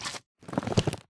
Sound / Minecraft / mob / magmacube / jump4.ogg
jump4.ogg